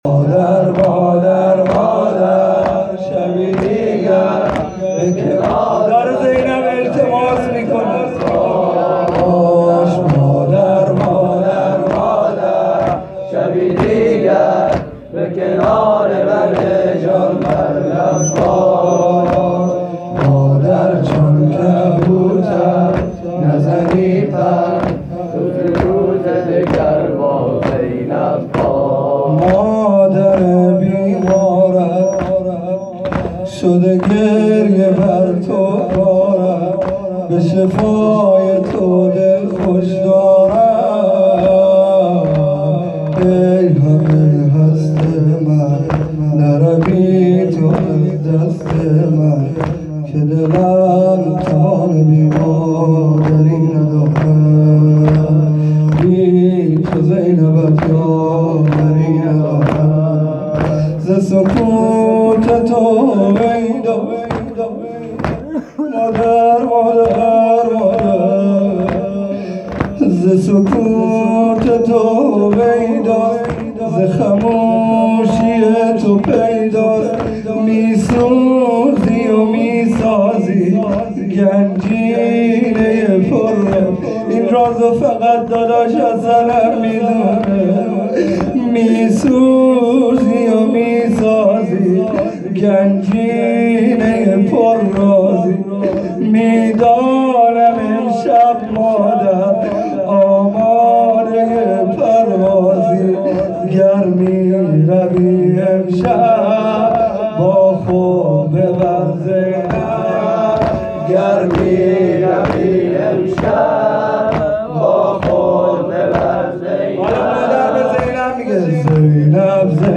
توضیحات: هیئت صادقیون حوزه علمیه زابل
زمینه-جدید.mp3